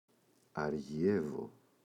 αργιεύω [a’rʝevo]